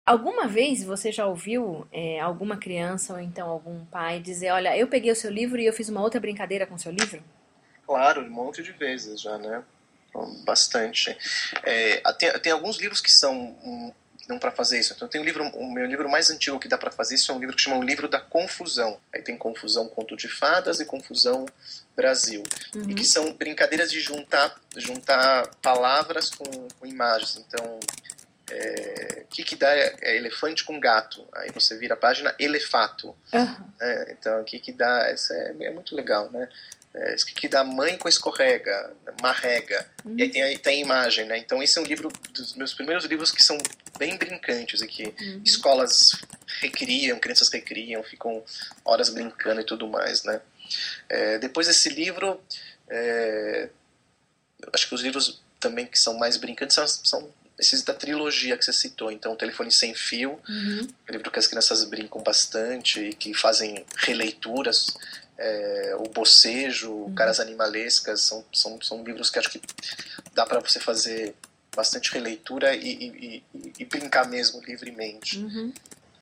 entrevista_livros_bricantes.mp3